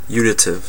Ääntäminen
Synonyymit combinative Ääntäminen Tuntematon aksentti: IPA : /ˈjuː.nɪ.tɪv/ Haettu sana löytyi näillä lähdekielillä: englanti Käännöksiä ei löytynyt valitulle kohdekielelle.